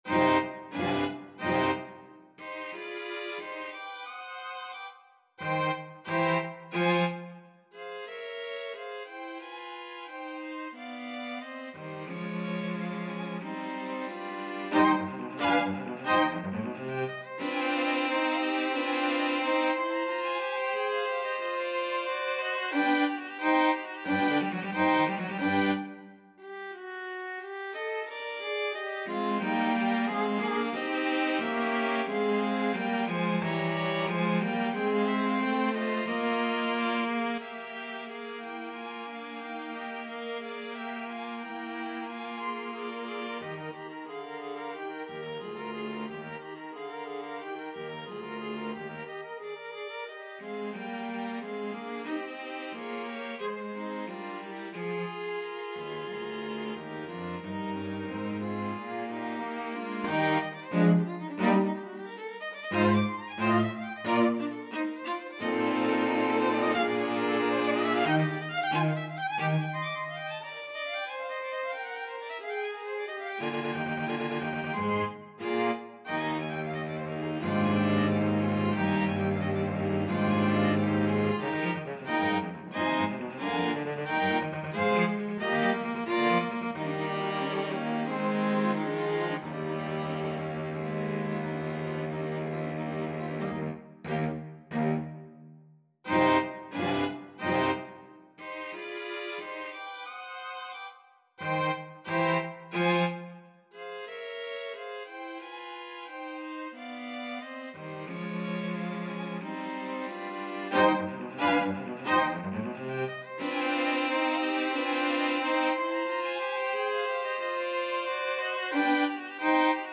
Free Sheet music for String Quartet
Violin 1Violin 2ViolaCello
4/4 (View more 4/4 Music)
C minor (Sounding Pitch) (View more C minor Music for String Quartet )
=90 Finale, presto
Classical (View more Classical String Quartet Music)